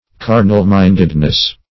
\Car"nal-mind"ed*ness\